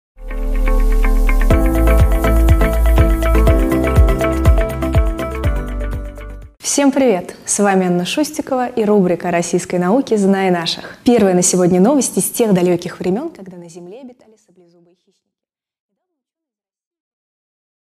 Аудиокнига О саблезубых кошках, черепах и лженауке | Библиотека аудиокниг